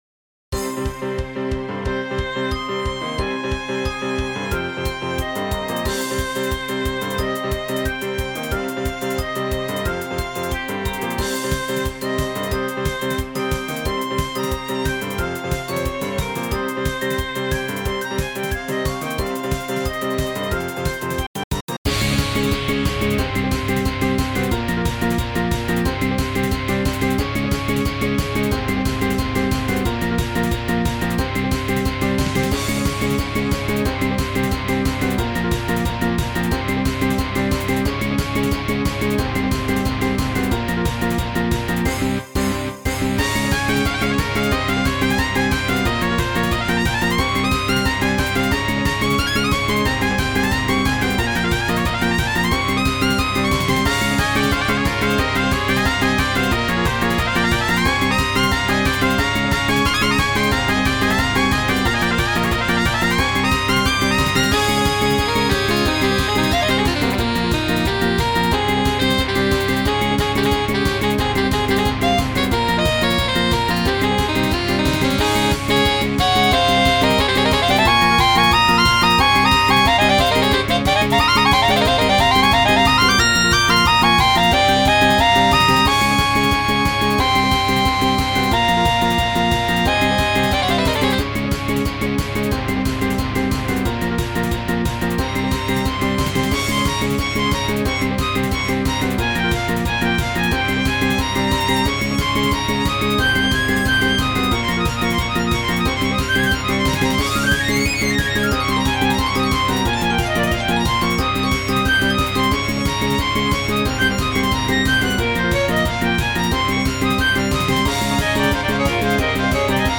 ジャンルPop
説明勢いで作ったごちゃごちゃソング